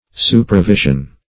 Supravision \Su`pra*vi"sion\, n.